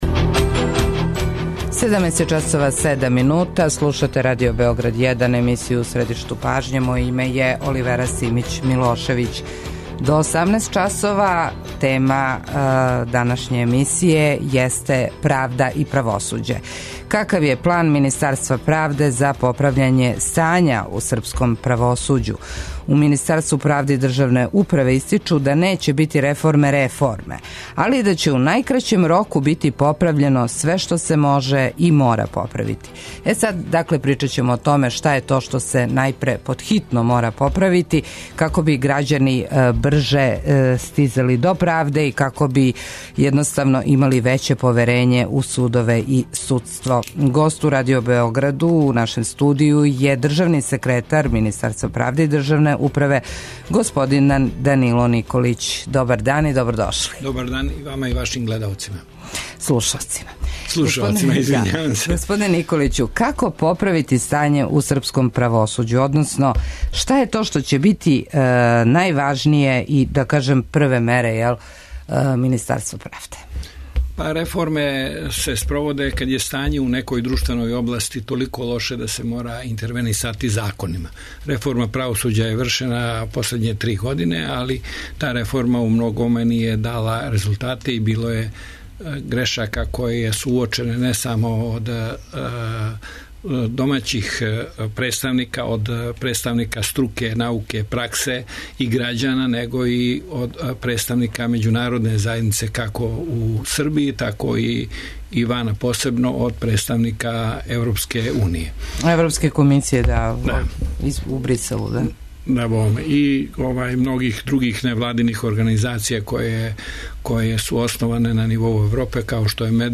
Гост емисије У средишту пажње је државни секретар Министарства правде и државне управе Данило Николић.